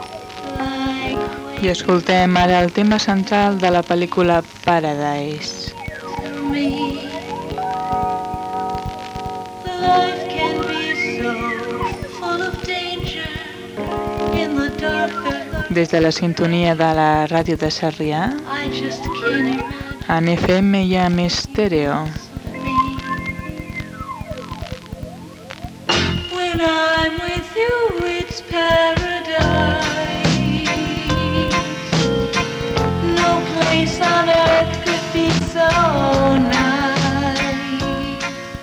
cd70c4c542812d24077e6d36c8cfca7fc167a61b.mp3 Títol Ràdio Sarrià Emissora Ràdio Sarrià Titularitat Tercer sector Tercer sector Barri o districte Descripció Identificació i presentació d'un tema musical.